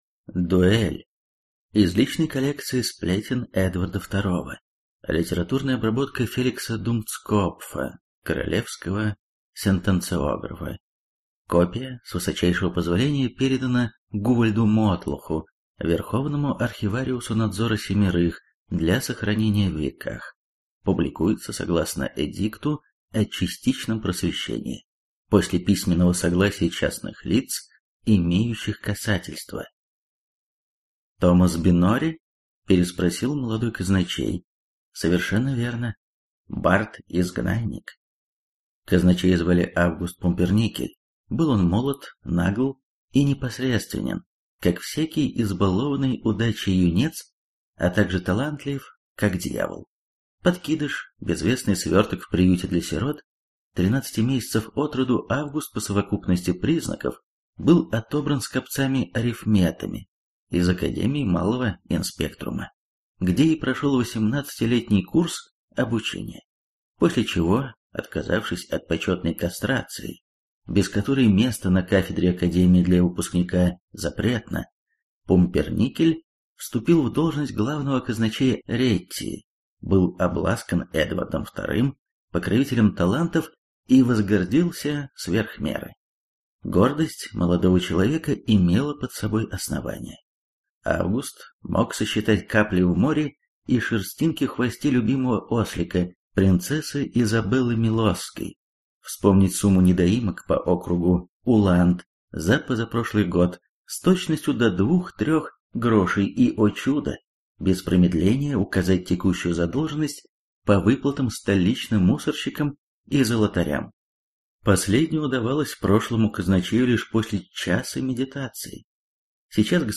Аудиокнига Рассказы очевидцев, или Архив Надзора Семерых | Библиотека аудиокниг